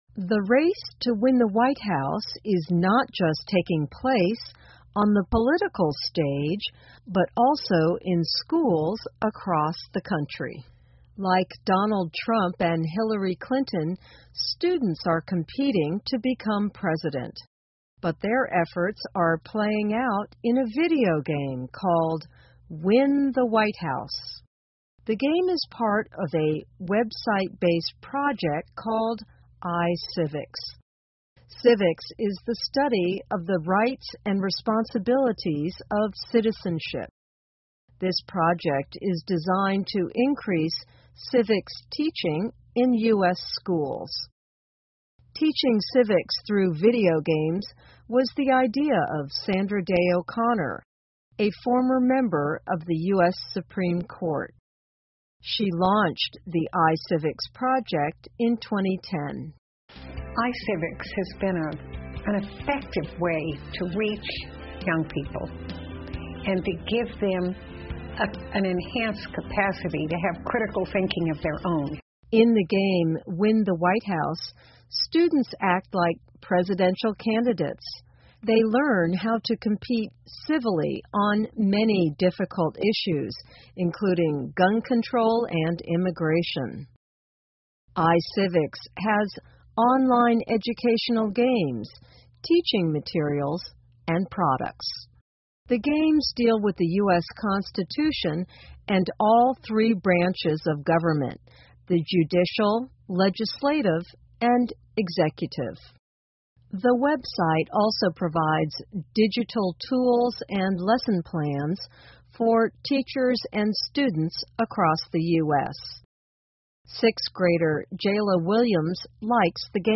VOA慢速英语--最高法院法官为公民设计游戏 听力文件下载—在线英语听力室